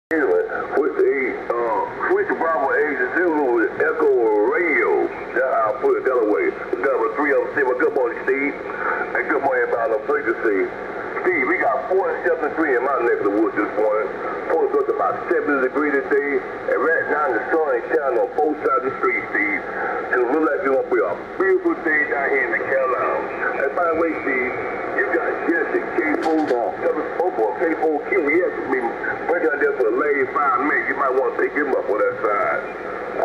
Ham Radio Transmissions